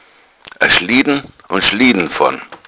Schliedn nein nicht Schlitten wie Rodel sondern weich ... i sooch schlieedn Wohl von gelare/gelasco = gefrieren; gelari = zu Eis erstarren; Gelatina = gefroren